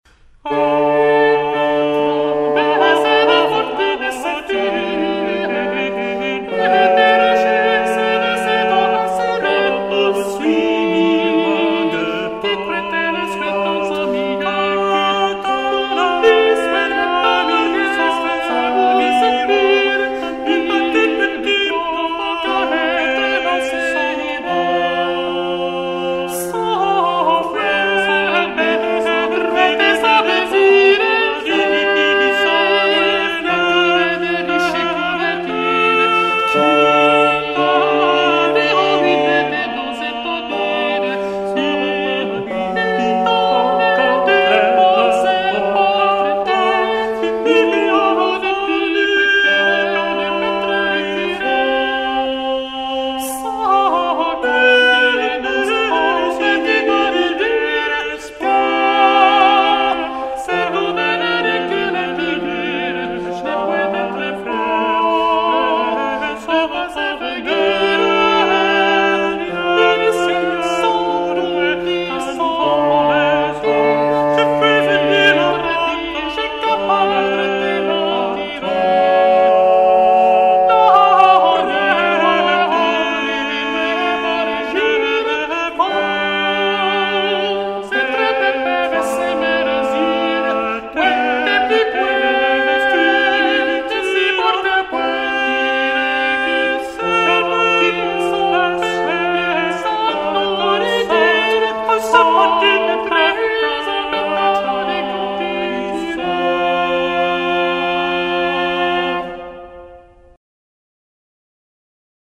MOTET
Oeuvre sacrée ou profane pour plusieurs voix, avec ou sans accompagnement musical. C'est la forme de musique vocale prépondérante au Moyen Age et à la Renaissance.
Le motet s'est développé à partir du XIIIème siècle, en reposant sur l'isorythmie (répétition d'un même schéma rythmique).